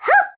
birdo_yah_wah_hoo1.ogg